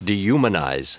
Prononciation du mot dehumanize en anglais (fichier audio)